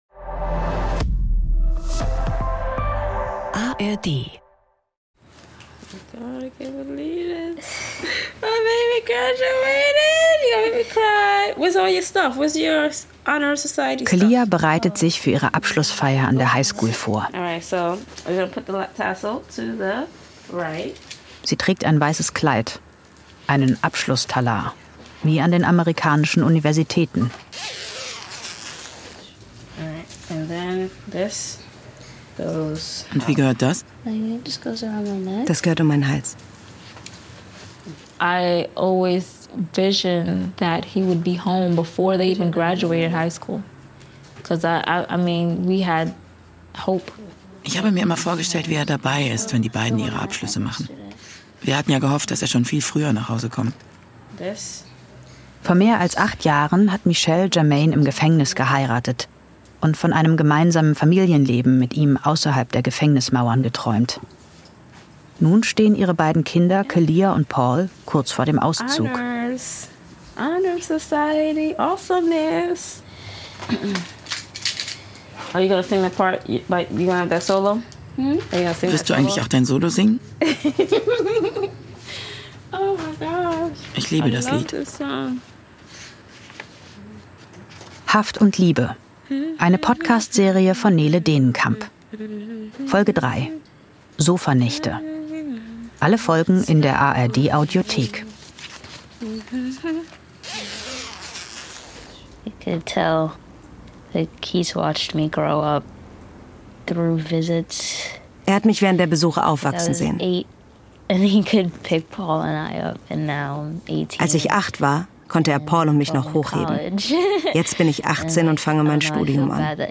(Stimme der) Erzählerin: